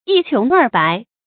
注音：ㄧ ㄑㄩㄥˊ ㄦˋ ㄅㄞˊ
讀音讀法：